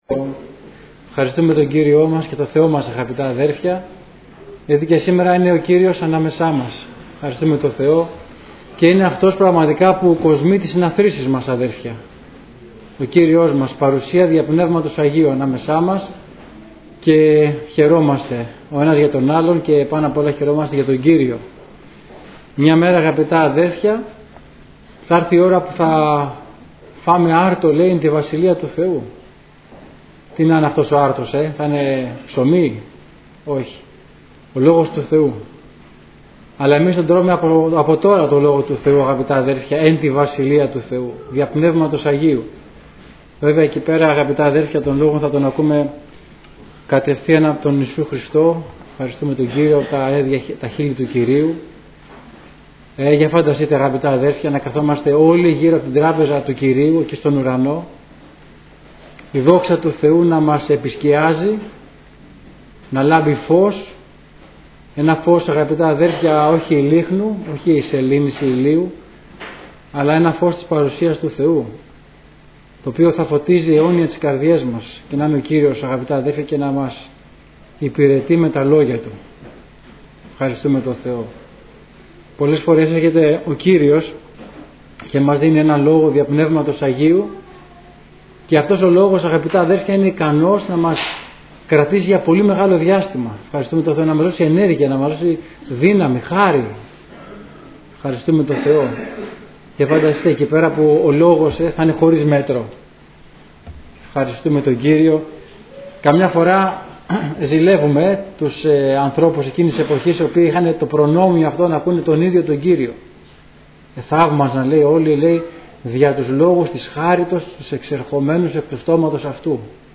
Κηρύγματα Ημερομηνία